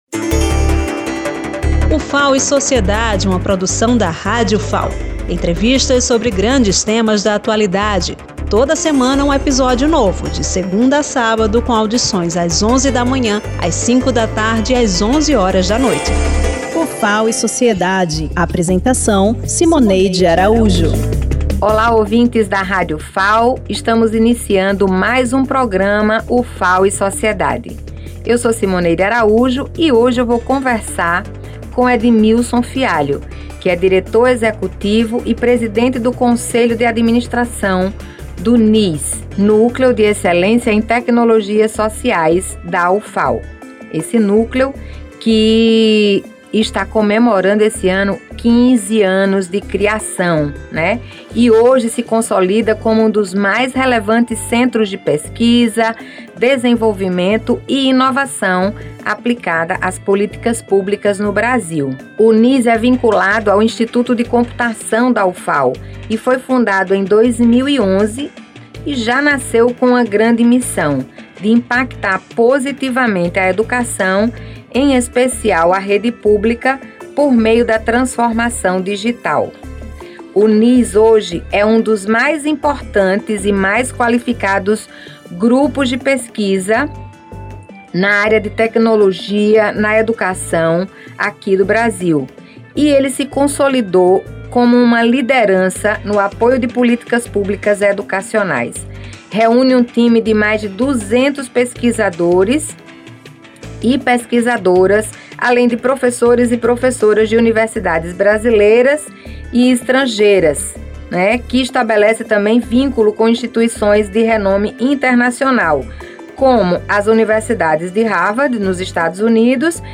Outro projeto de grande impacto abordado na entrevista é o Programa Pé-de-Meia, política pública do Governo Federal voltada à permanência de estudantes no ensino médio.